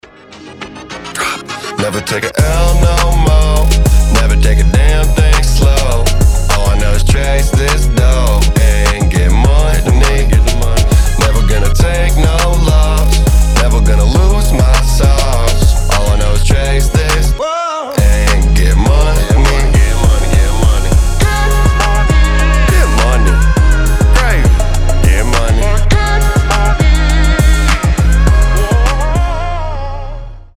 Хип-хоп
Synth Pop
pop rap
Прикольный рэпчик из Тик Тока